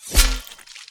melee-hit-11.ogg